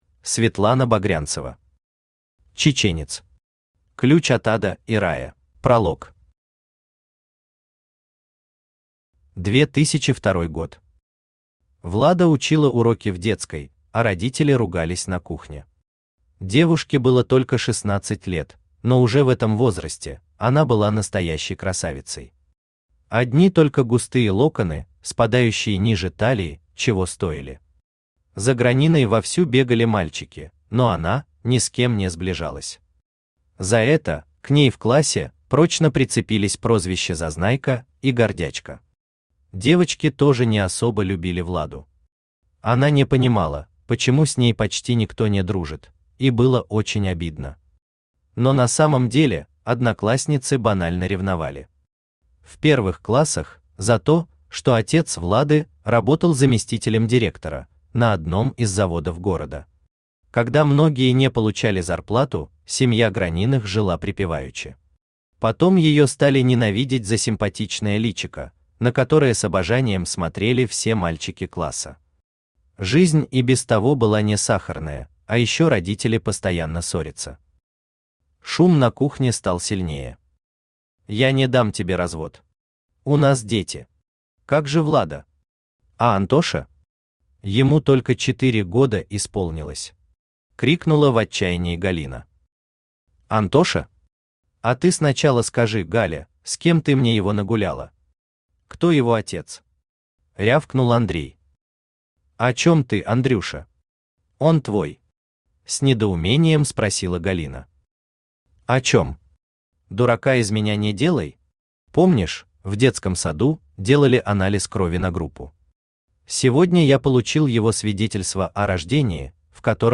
Ключ от ада и рая Автор Светлана Багрянцева Читает аудиокнигу Авточтец ЛитРес.